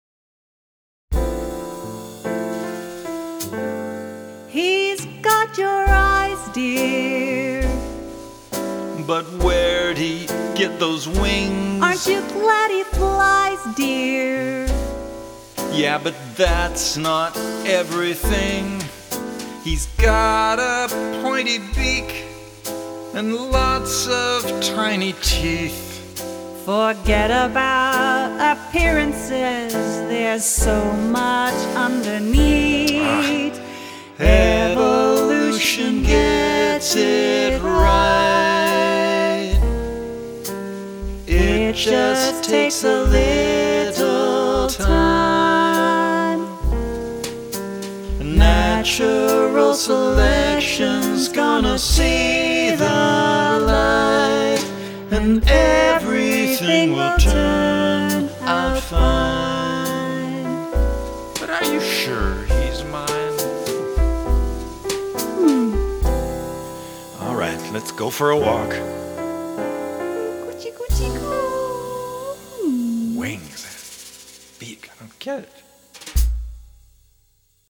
Why does his son have wings and feathers, like an archaeopteryx? Betty assures Bert that “everything will turn out fine” and both sing: Evolution Gets It Right